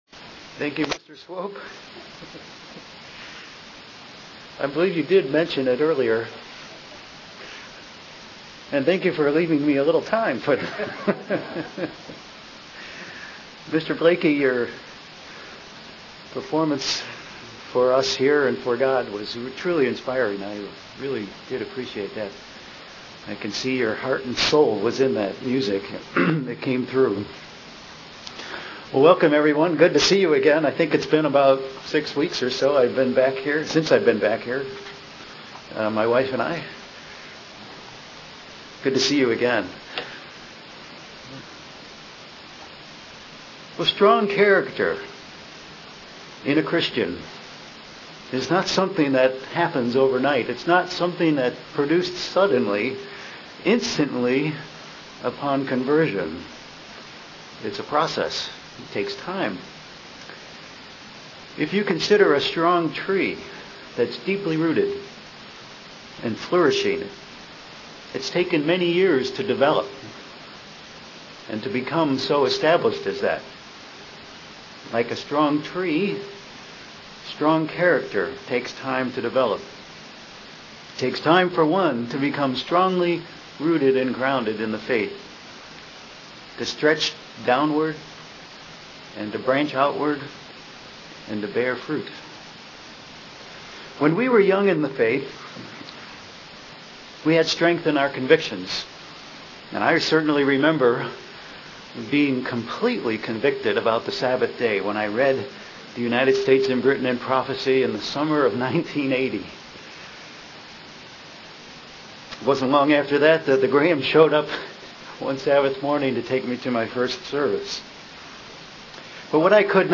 Given in Cleveland, OH
Let's review some of the ways and qualities we as faithful believers can be deeply rooted like a strong tree UCG Sermon Studying the bible?